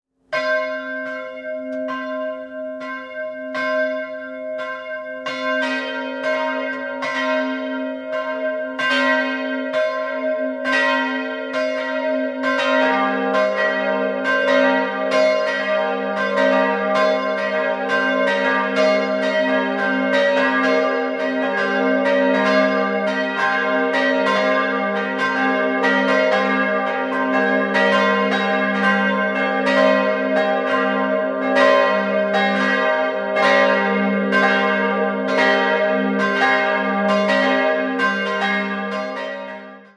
Glocke 1 g'+5 625 kg 1.010 mm 1951 Johann Hahn, Landshut Glocke 2 b'+4 350 kg 830 mm 1755 Mathias Perner, Eichstätt Glocke 3 c''+3 260 kg 767 mm 1951 Johann Hahn, Landshut Glocke 4 c'''-4 35 kg 396 mm 1694 Johann Balthasar Herold, Nürnberg Quelle: Amt für Kirchenmusik, Eichstätt